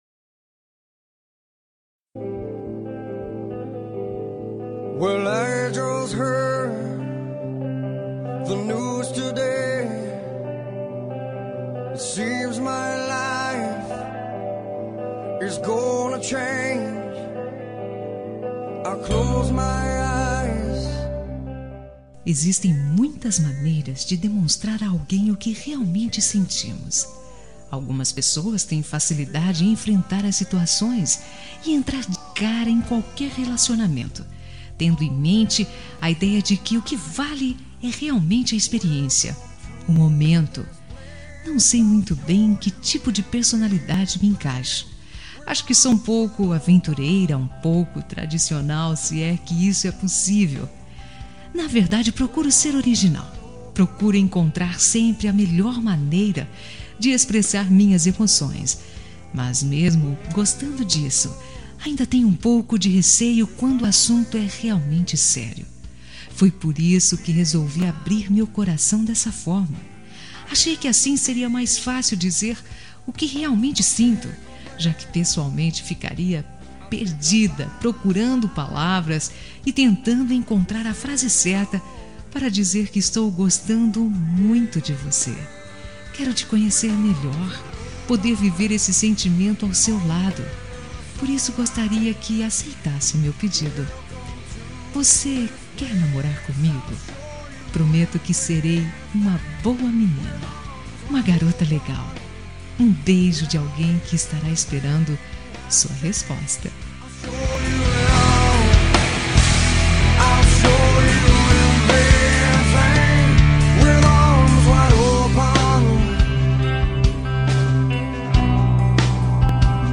Telemensagem de Pedido – Voz Feminina – Cód: 20185 – Quer Namorar